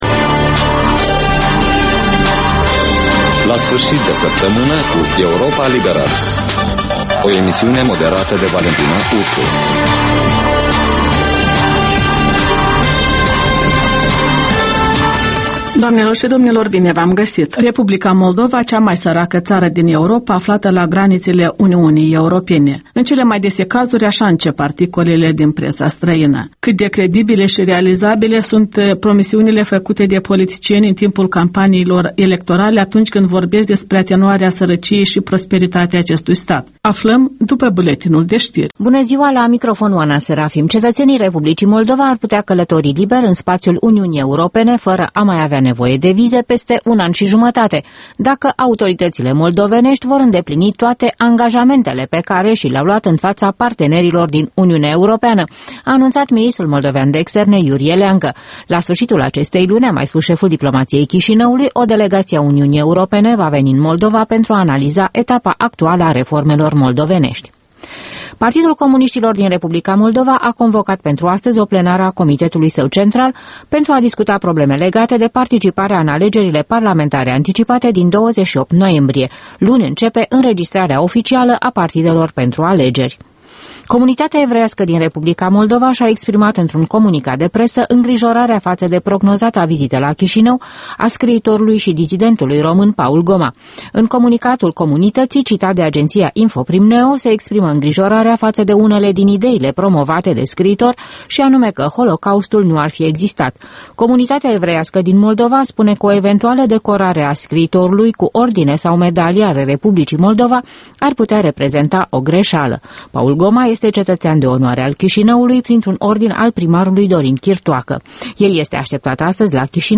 In fiecare sîmbătă, un invitat al Europei Libere semneaza "Jurnalul săptămînal".